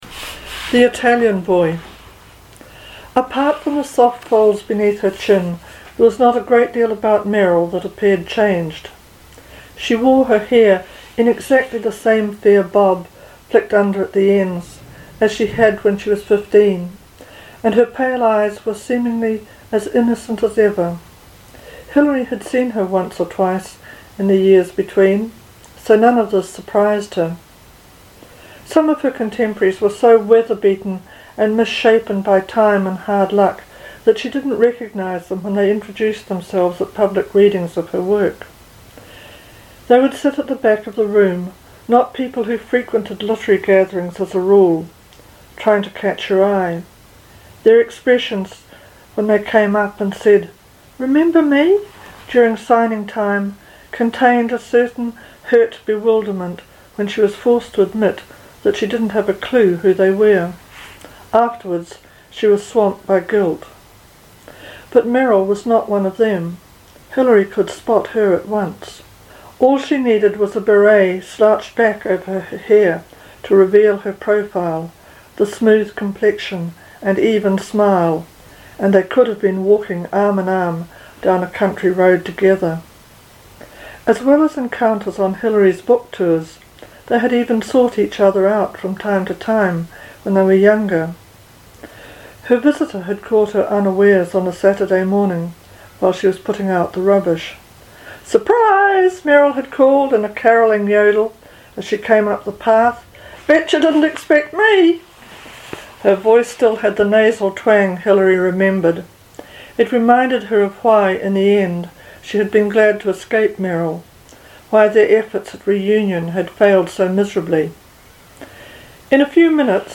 Fiona Kidman reading